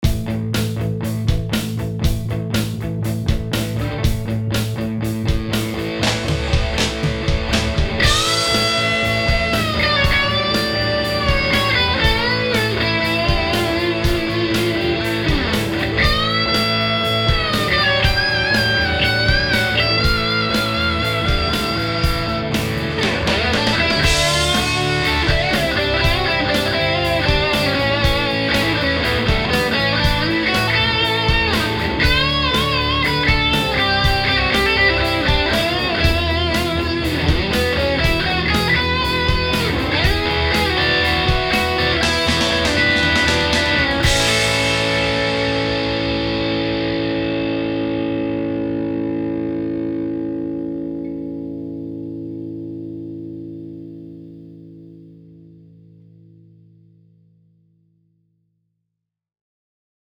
So I swapped them out for a set of NOS circa 1959 GE and RCA long plate 12AX7’s, which are oh-so-smooth and a have a bit less gain than the JJ’s. The result was simply magnificent!
That clip was recorded with the Aracom PLX18 BB, and using my LP copy Prestige Heritage Elite.
Now, I can crank that puppy up, and get those rich tones with no fizz.